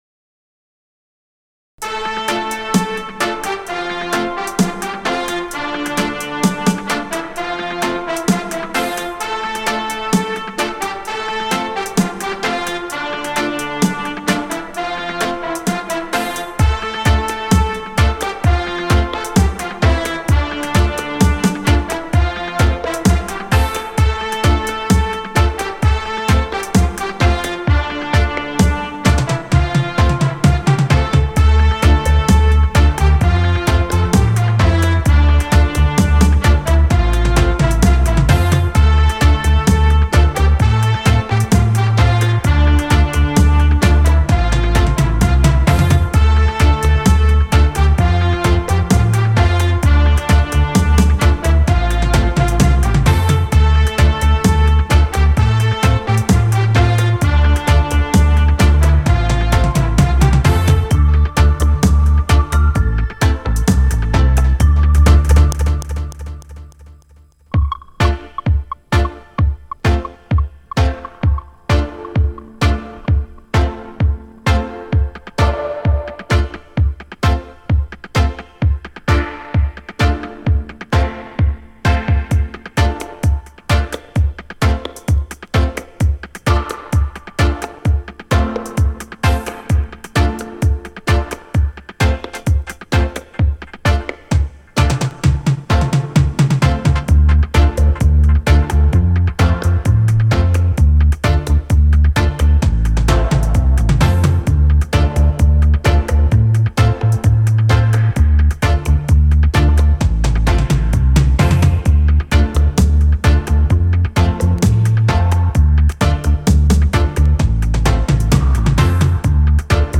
Dub Stepper